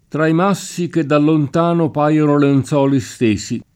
tra i m#SSi ke dda llont#no p#Lono lenZ0li St%Si] (Cicognani) — dim.